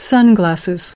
13. Sunglasses /ˈsʌnɡlæsɪz/: kính mát, kính râm